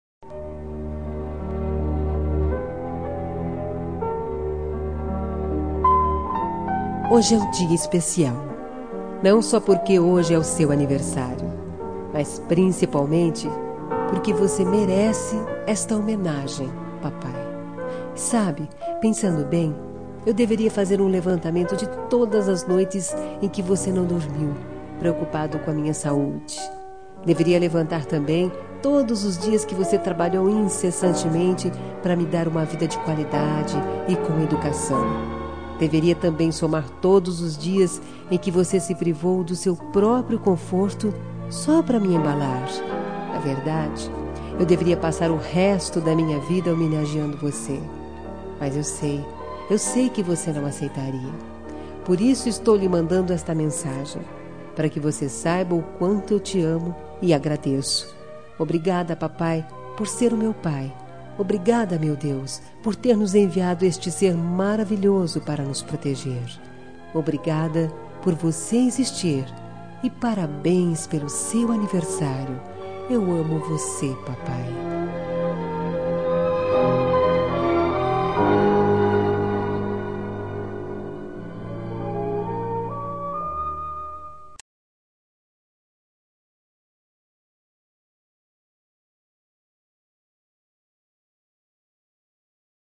Telemensagem de Aniversário de Pai – Voz Feminina – Cód: 1459